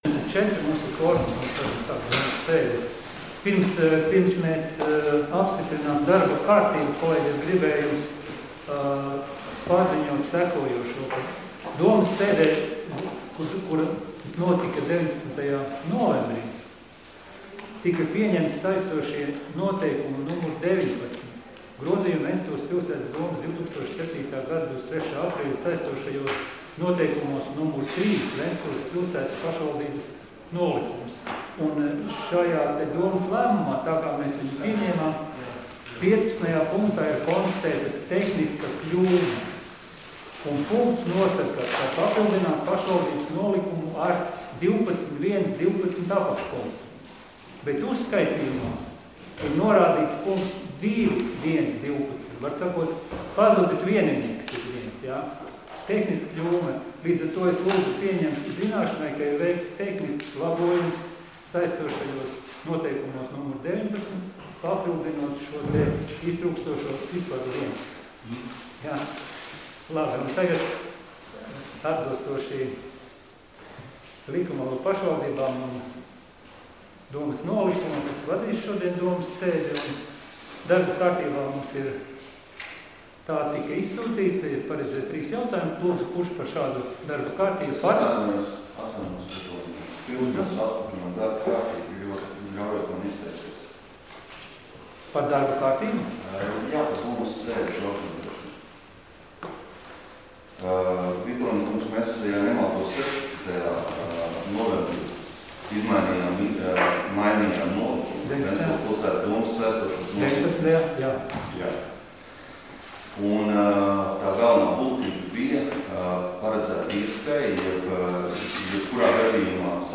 Domes sēdes 03.12.2020. audioieraksts